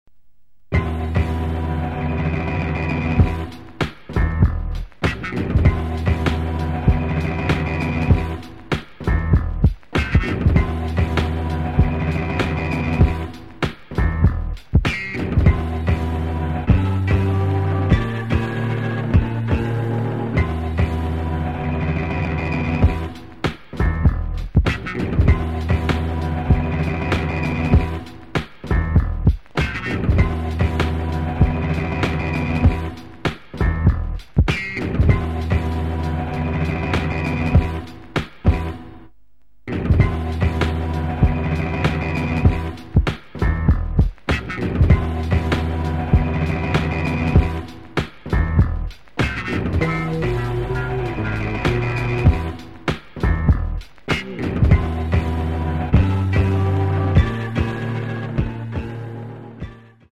The EP closes with a club banger.